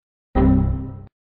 Звуки ошибок
На этой странице собраны разнообразные звуки ошибок из операционных систем, программ и игр.
1. Ошибка Windows XP